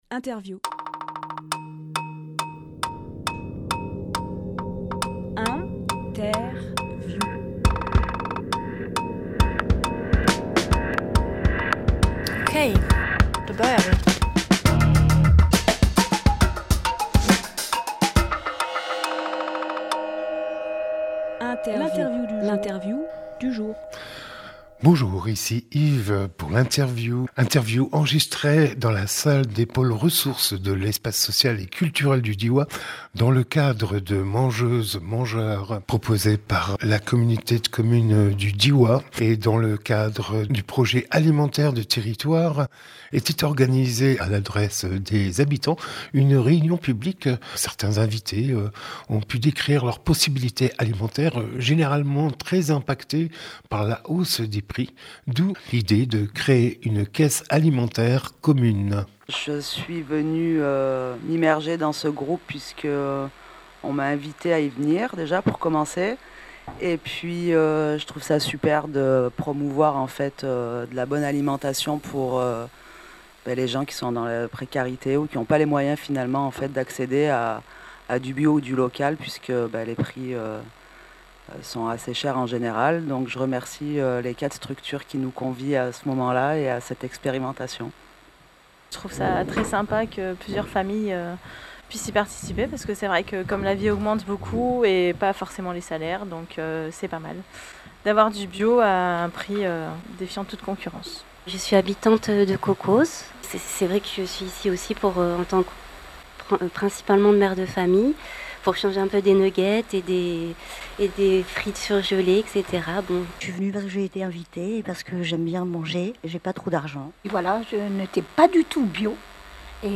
Emission - Interview Une caisse alimentaire commune par Vrac Drôme Publié le 7 décembre 2023 Partager sur…